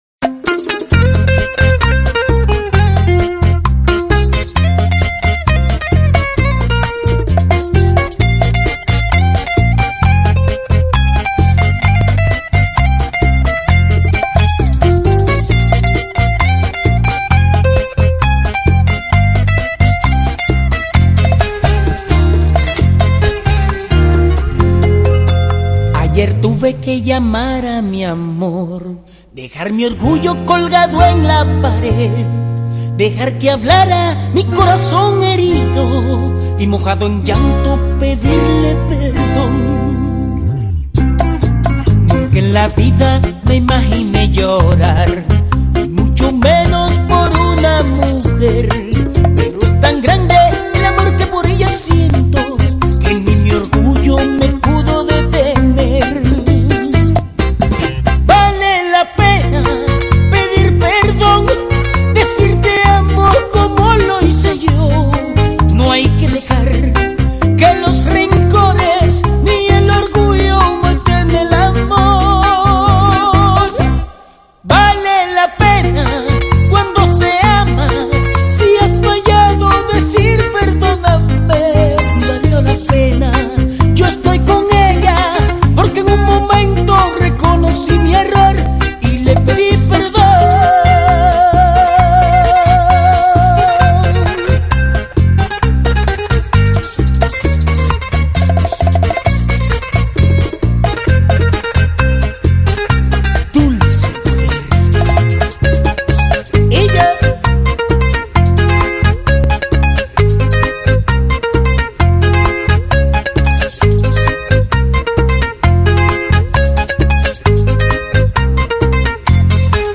Bachata (закрыта)